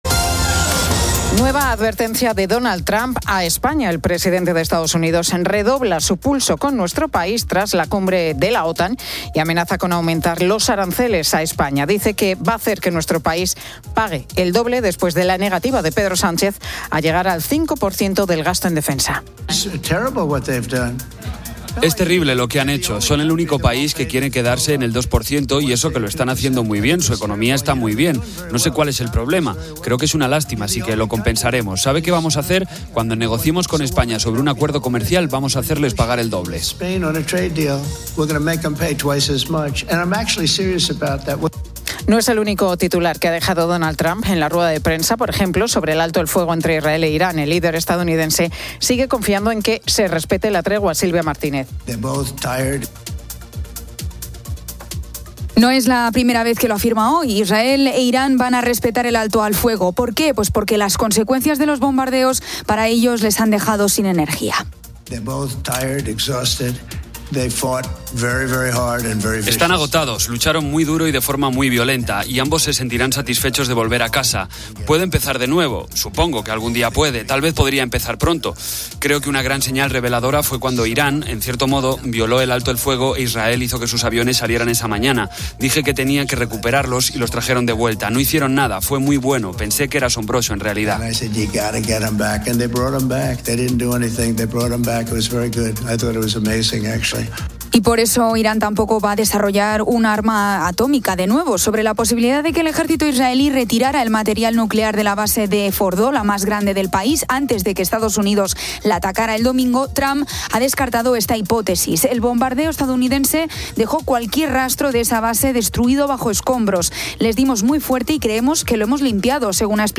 La Tarde 17:00H | 25 JUN 2025 | La Tarde Pilar García Muñiz entrevista al grupo Tennessee, que cumple 45 años en la música.